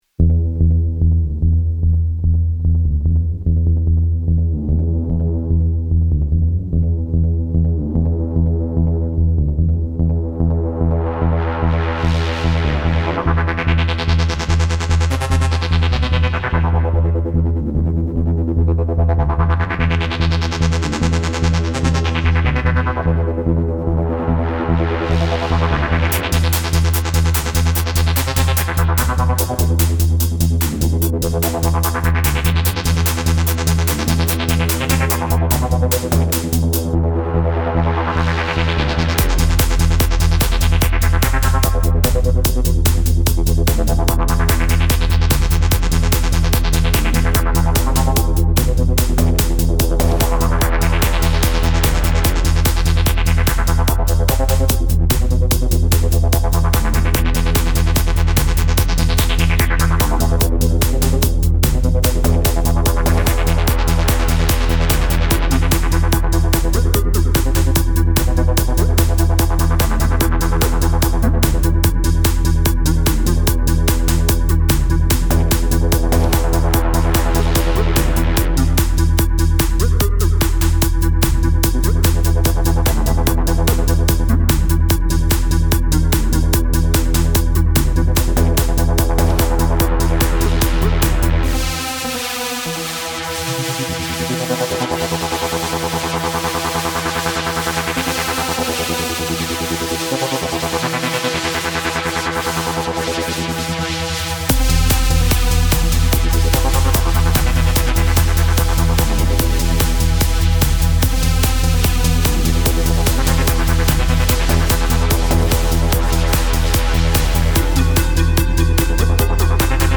A dark EDM-style tune fit for an escape!